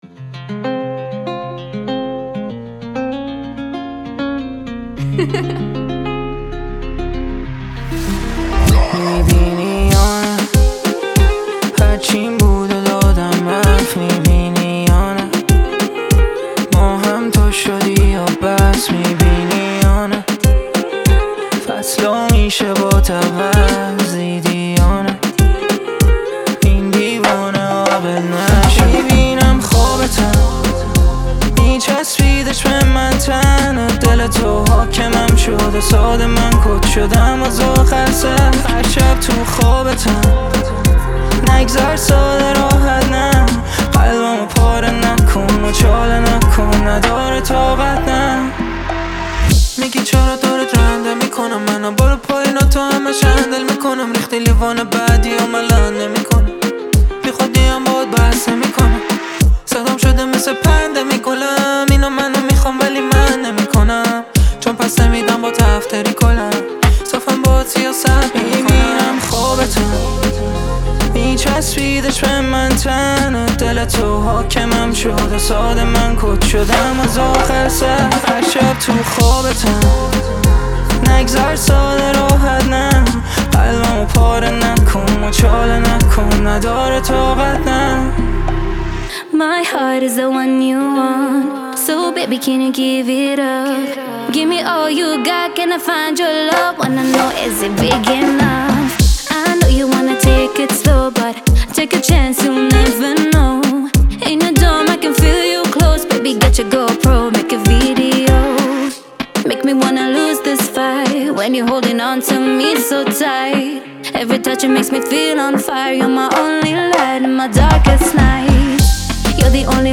پاپ رپ آر اند بی